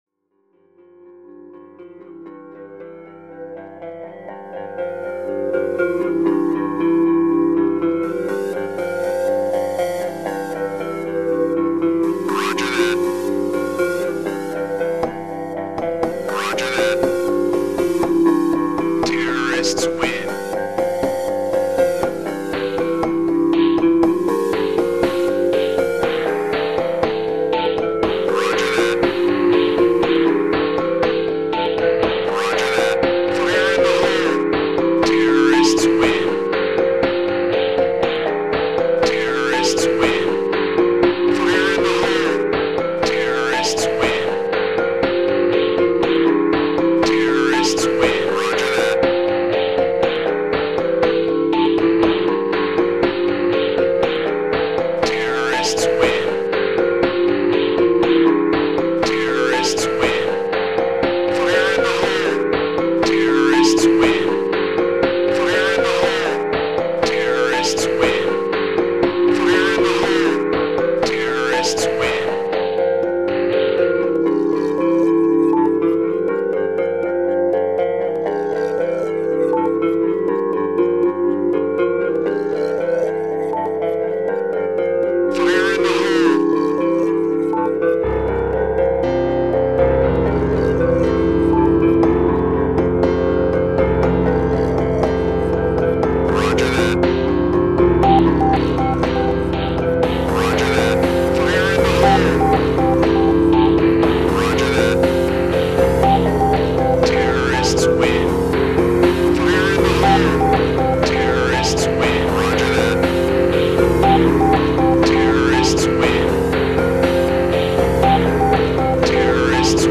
CS Samples und eine bittere Erkenntnis...